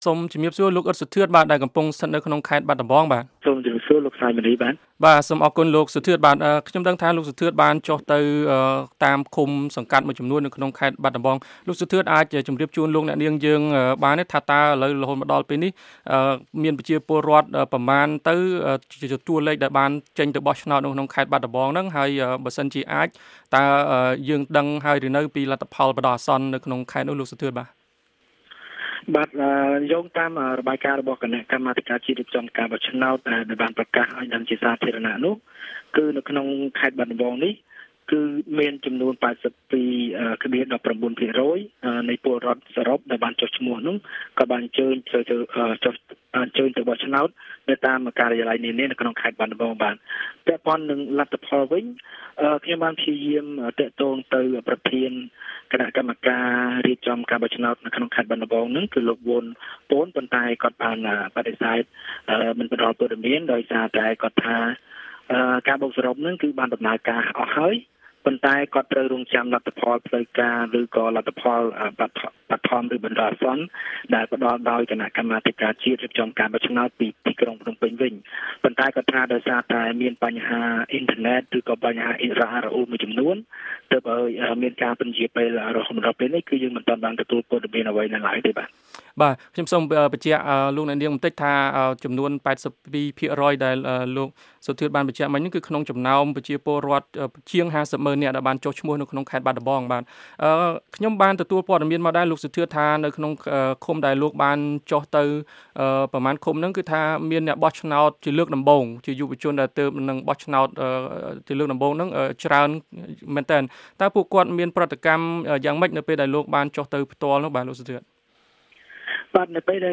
បទសម្ភាសន៍ VOA៖ ស្ថានភាព និងលទ្ធផលបឋមនៃការបោះឆ្នោតឃុំ-សង្កាត់នៅខេត្តបាត់ដំបង